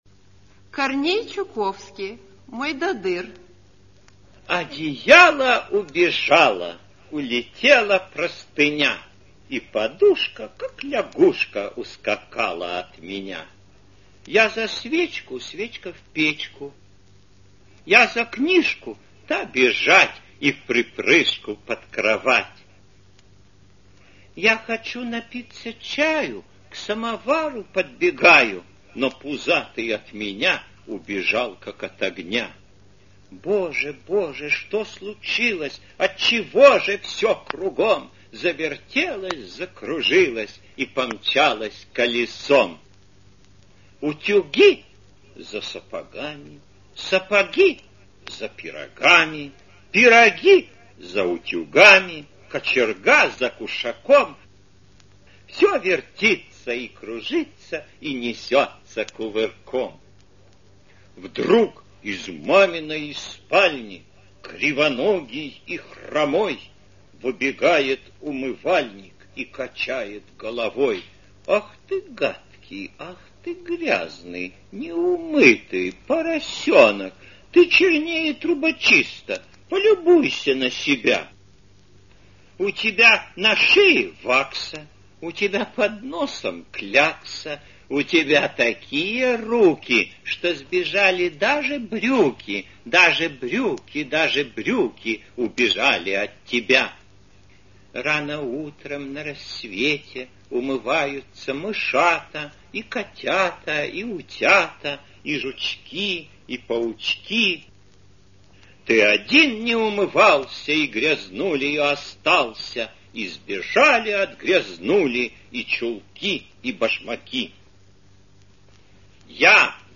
Корней Чуковский читает автор – Мойдодыр
kornej-chukovskij-chitaet-avtor-mojdodyr